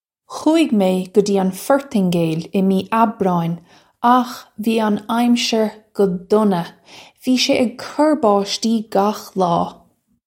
Khoo-ig may guh jee un Fortin-gale ih mee Ab-raw-in akh vee un ime-sher guh dunna - vee shay ig kur bawshtee gakh law.
This is an approximate phonetic pronunciation of the phrase.